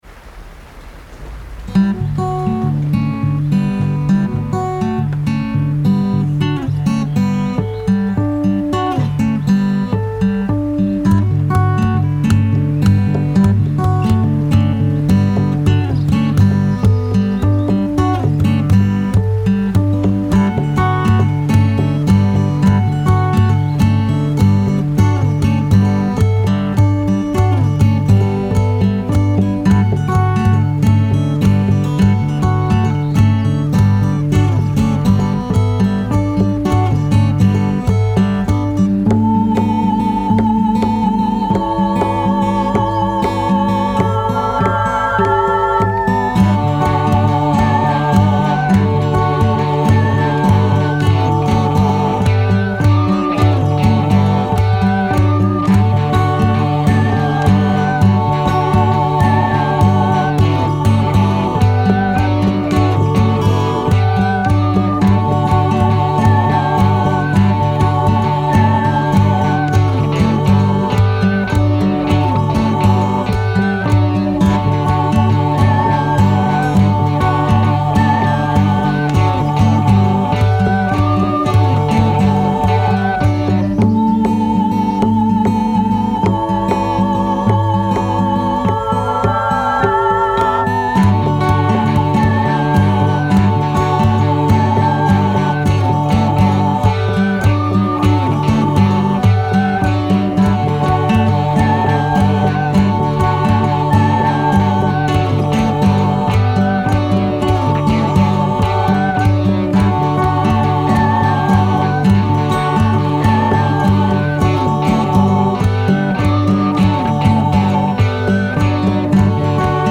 i learned that i did really like fingerpicking as a kind of meditative activity
acoustic guitar instrumental